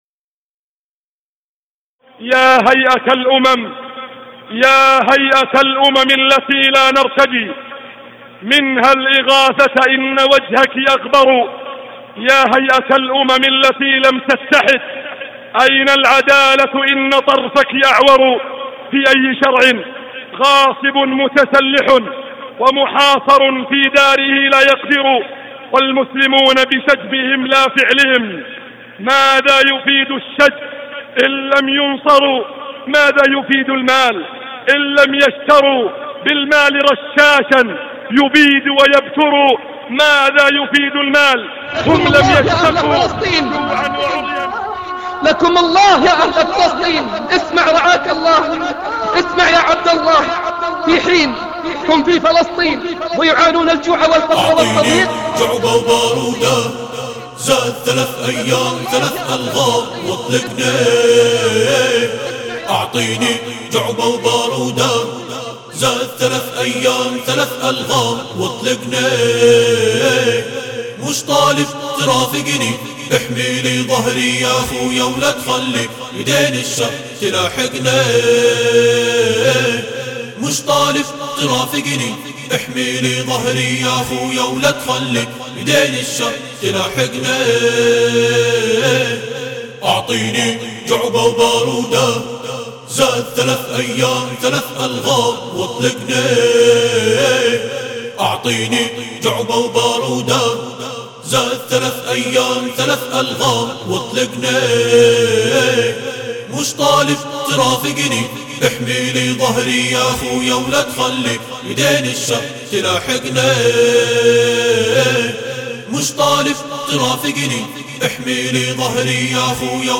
أبدع منشدنا في تقليد الصوت واللحن